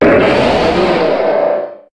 Cri de Méga-Jungko dans Pokémon HOME.
Cri_0254_Méga_HOME.ogg